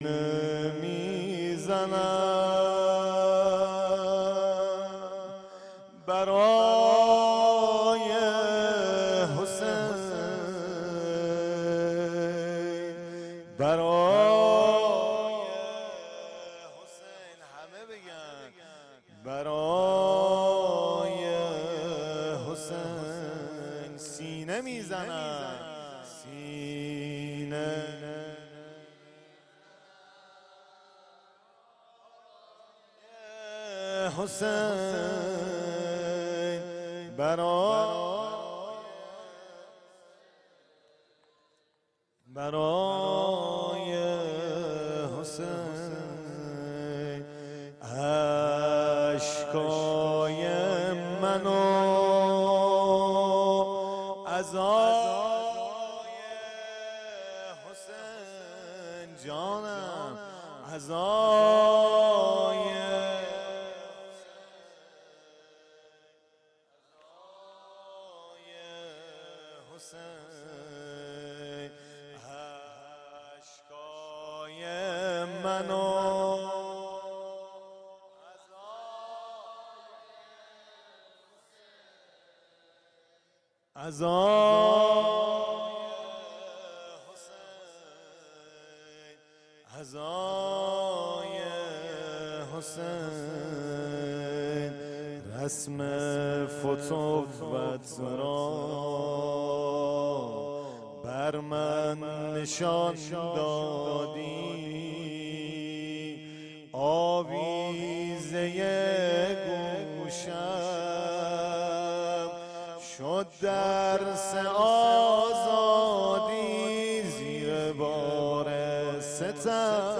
مسجد جامع مهدی (عج)
زمزمه
شب اول محرم 1398